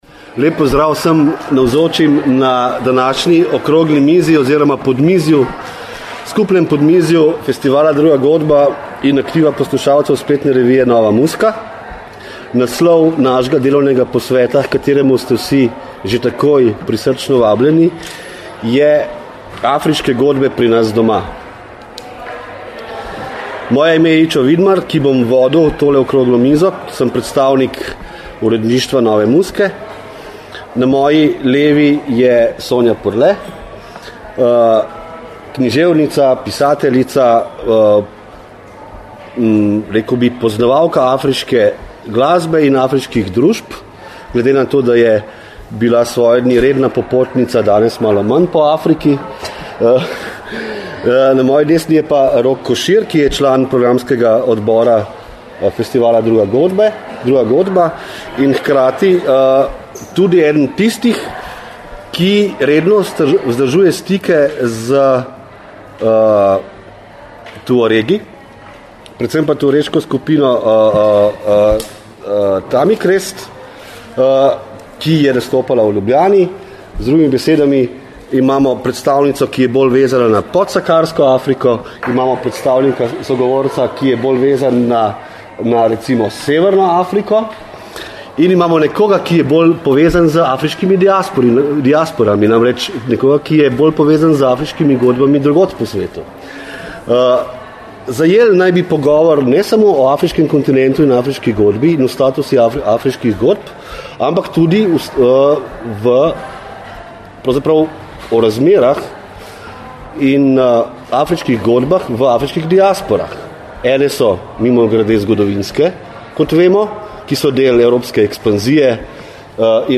Oziroma okrogla miza Afriške godbe pri nas doma v okviru letošnje 28. Druge godbe.